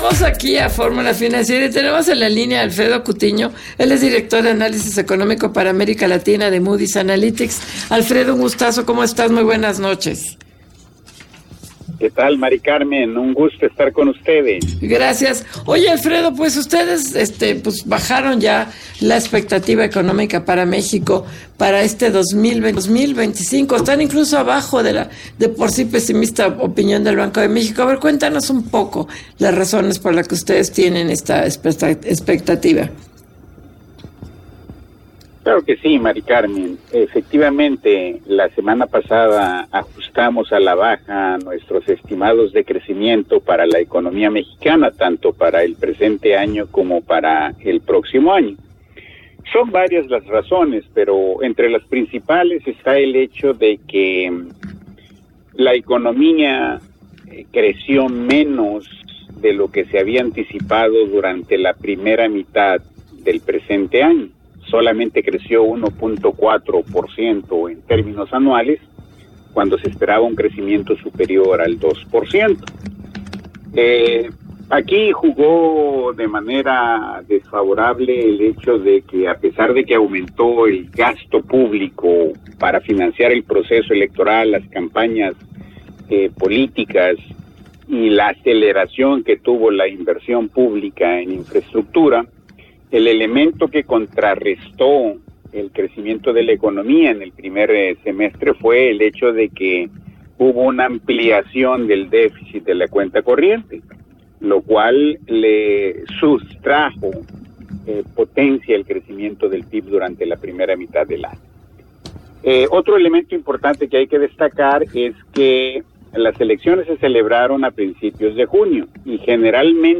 Entrevista en el programa F�rmula Financiera de Radio F�rmula